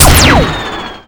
gun2.wav